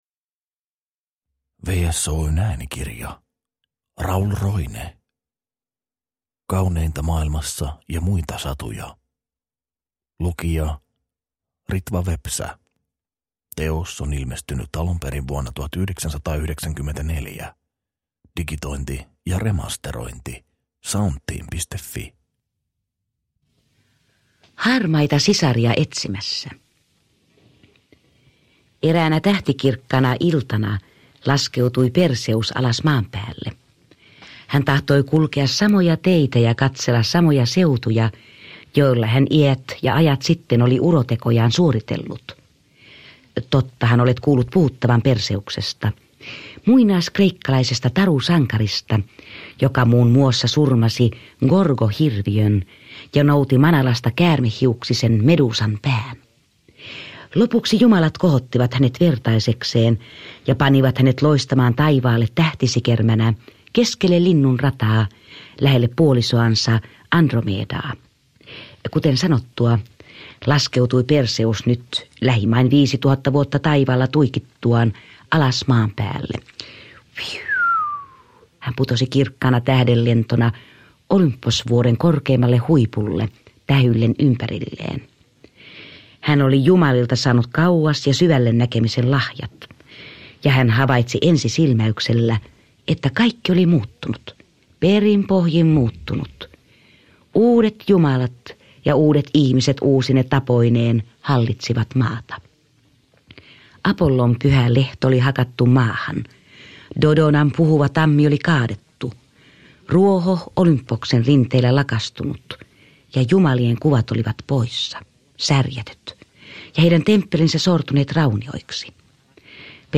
Kauneinta maailmassa ja muita satuja – Ljudbok – Laddas ner